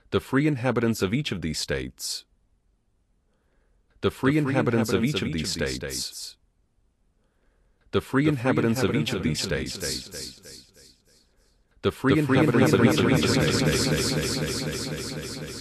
Echo_samples.ogg.mp3